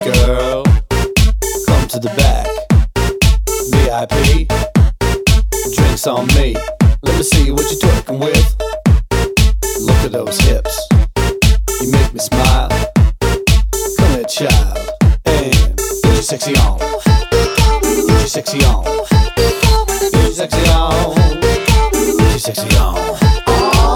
R'n'B / Hip Hop 3:59 Buy £1.50